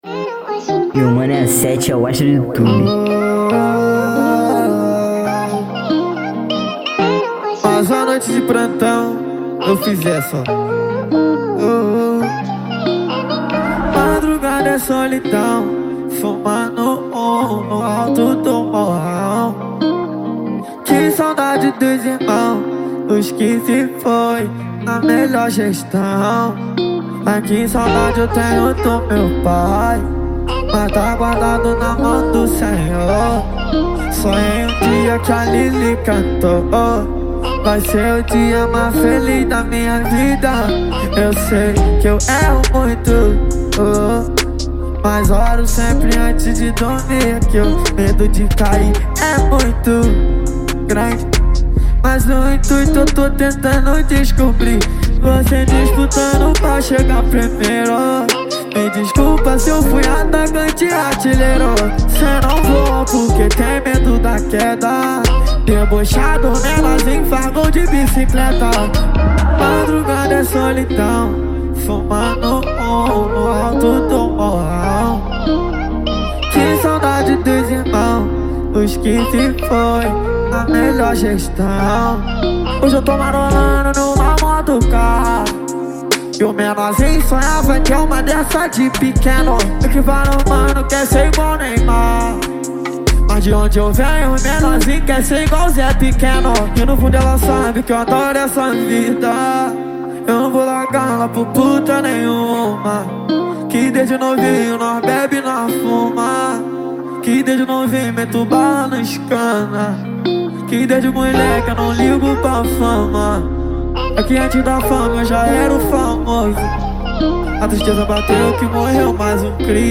2024-11-22 08:58:02 Gênero: Trap Views